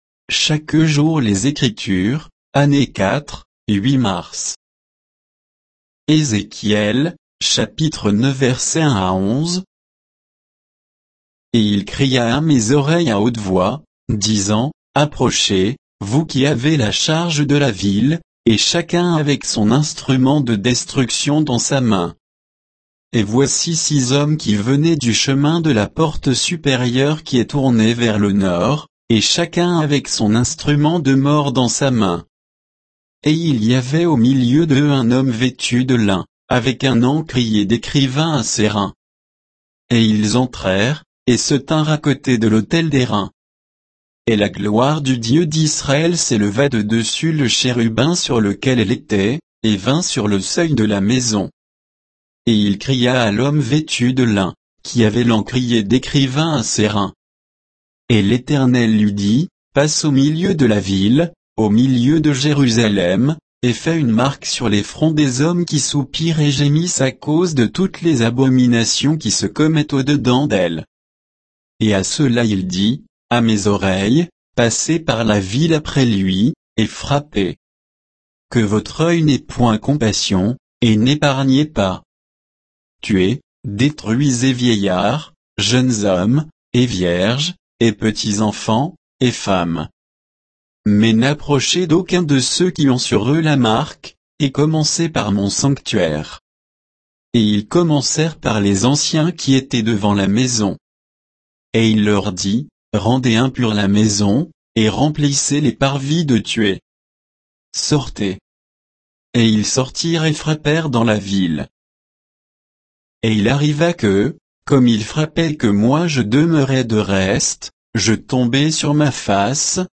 Méditation quoditienne de Chaque jour les Écritures sur Ézéchiel 9, 1 à 11